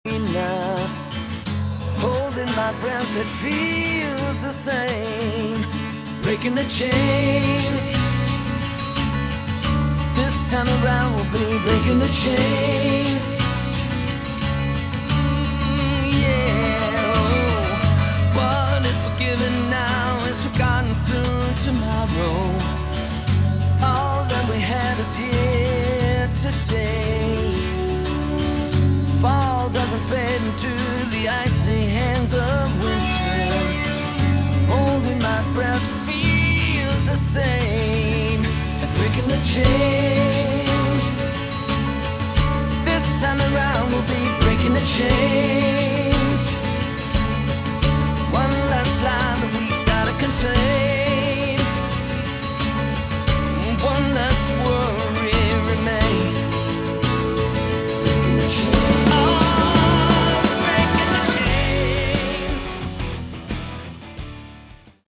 Category: AOR / Melodic Rock
lead vocals
guitars, bass, keyboards, vocals
keyboards, piano, vocals
drums